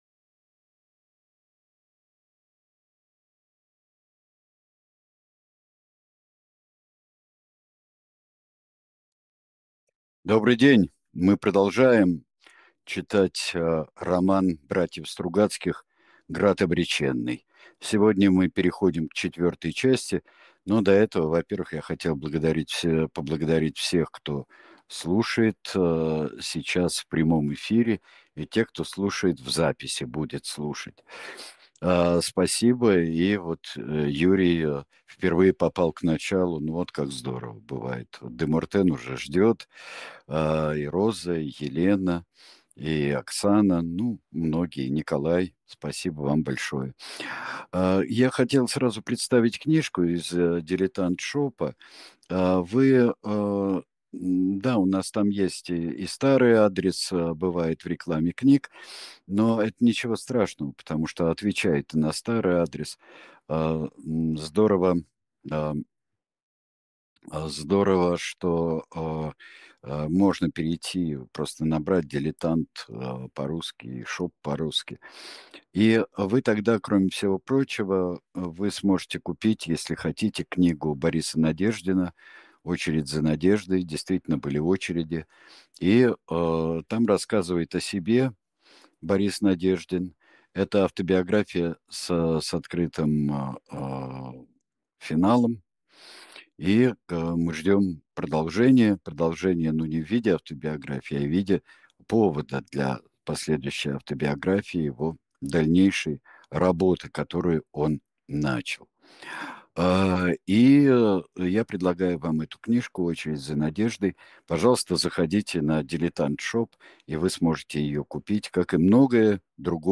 Читает произведение Сергей Бунтман